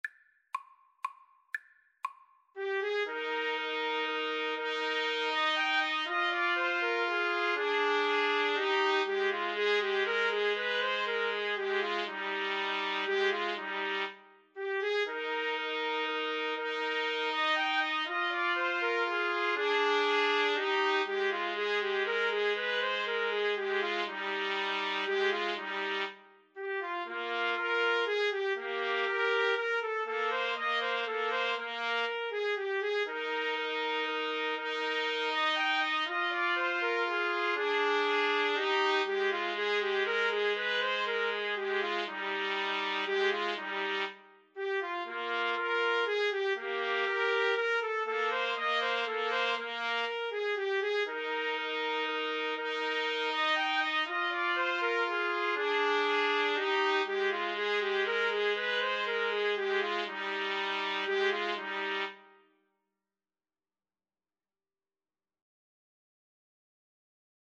3/4 (View more 3/4 Music)
Classical (View more Classical Trumpet Trio Music)